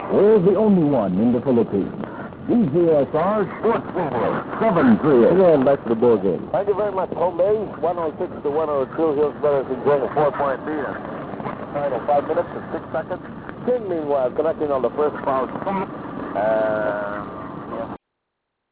kHz:  radio station, location:  recorded in:  submitted by: